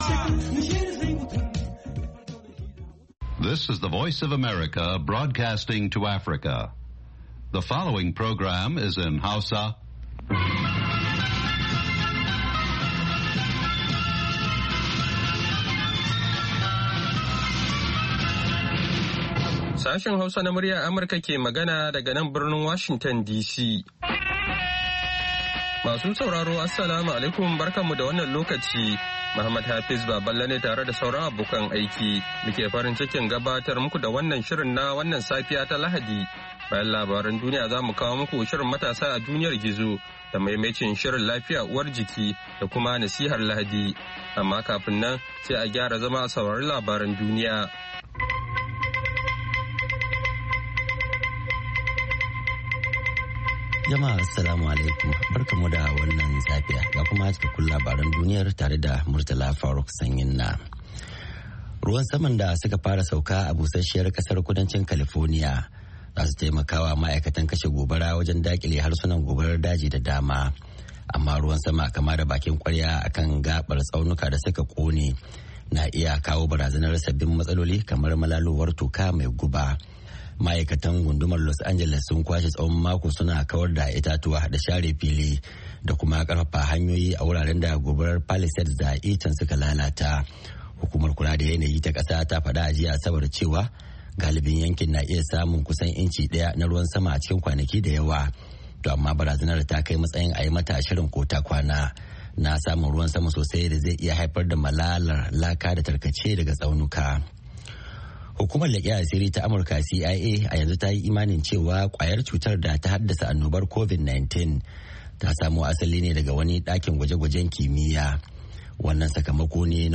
Kullum da karfe 6 na safe agogon Najeriya da Nijar muna gabatar da labarai da rahotanni da dumi-duminsu, sannan mu na gabatar da wasu shirye-shirye kamar Noma da Lafiya Uwar Jiki.